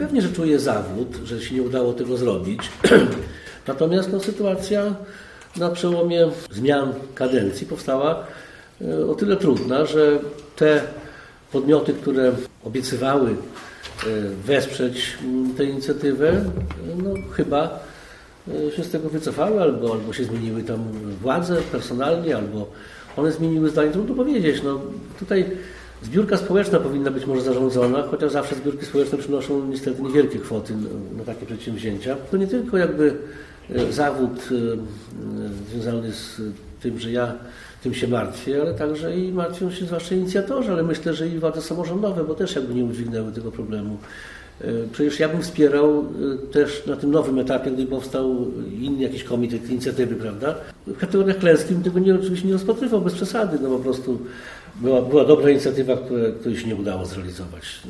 – Czuje zawód, że nie udało się tego zrobić – mówi o pomniku Aleksandry Piłsudskiej Jarosław Zieliński, poseł Prawa i Sprawiedliwości z Suwałk.